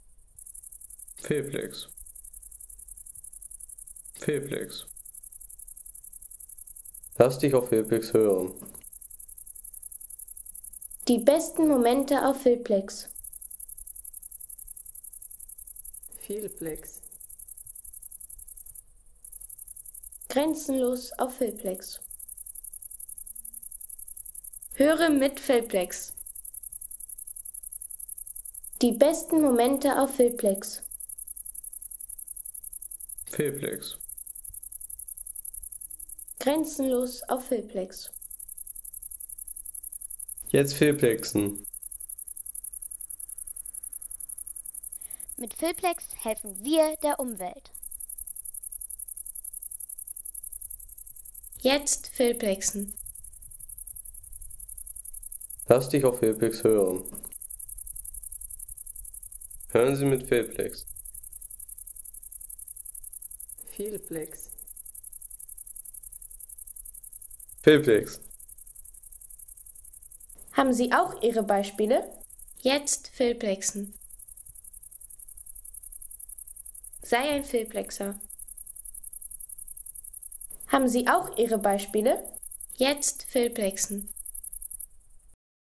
Das Grüne Heupferd